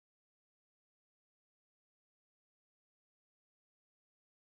sounds and music coming from the Southwest Asia and North Africa